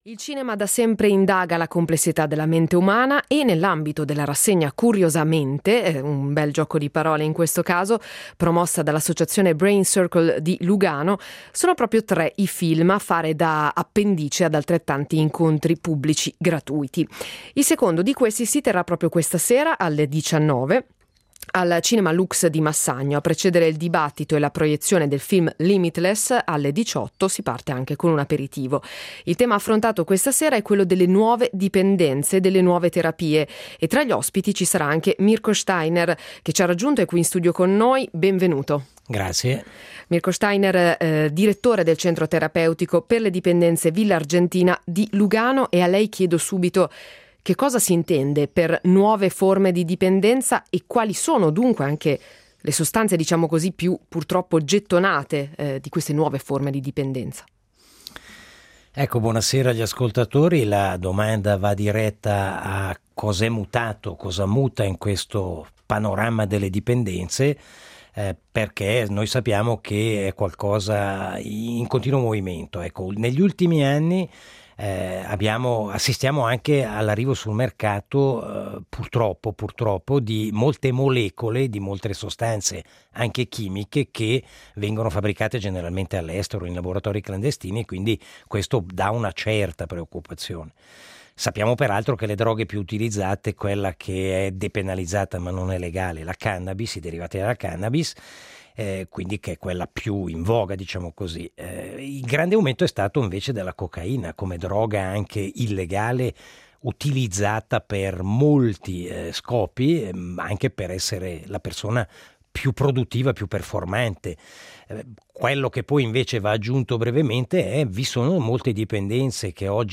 che è stato anche nostro ospite in studio.